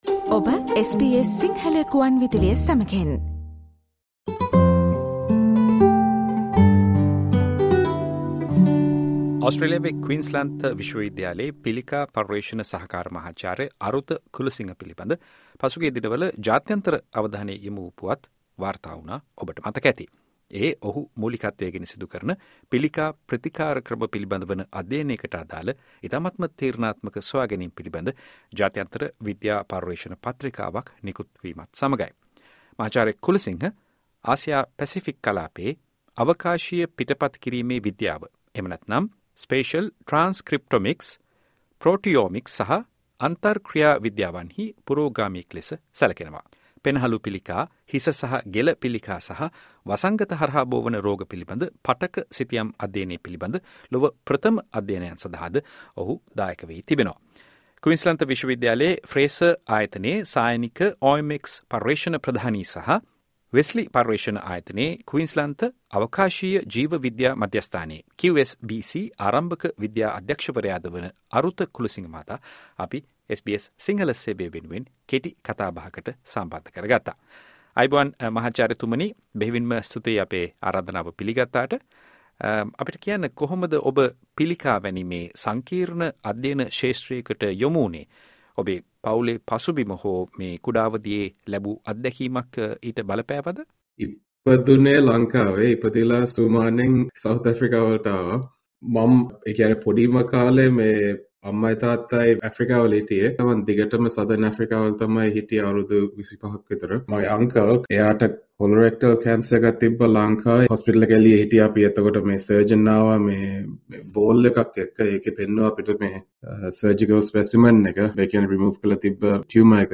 SBS සිංහල සේවය පැවත්වූ සාකච්ඡාව.